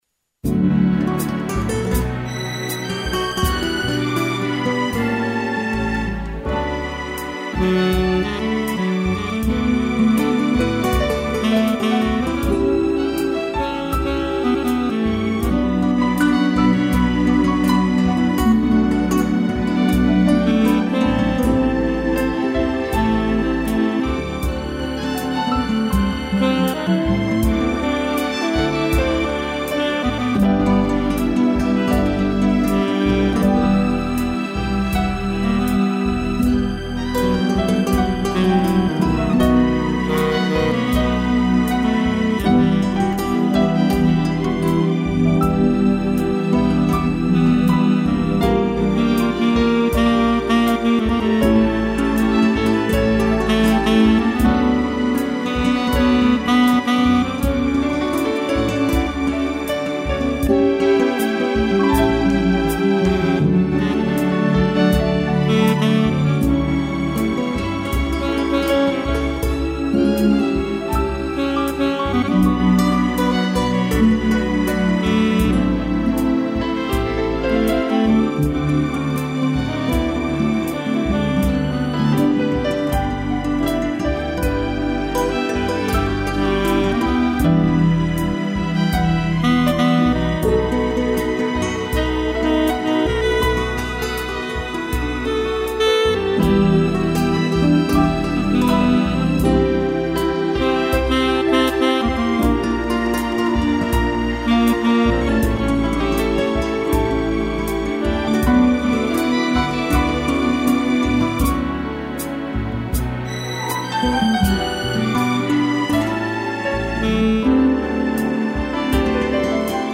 violão
piano